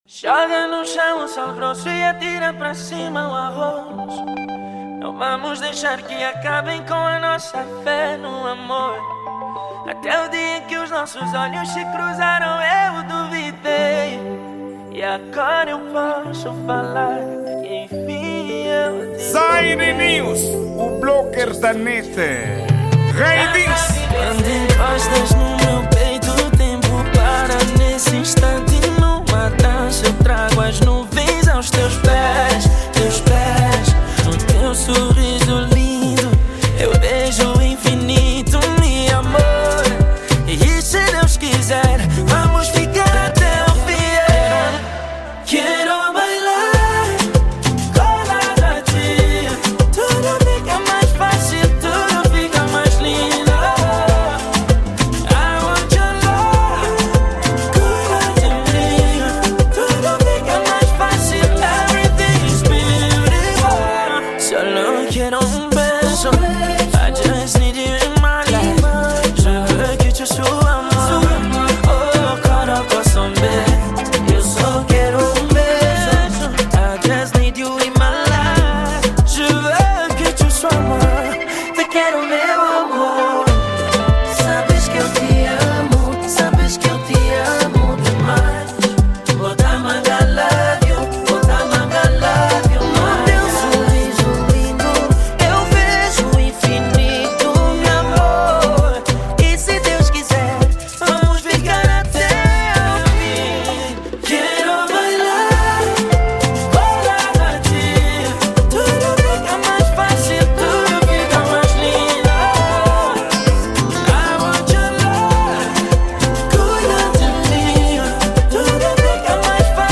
Gênero:Afro Pop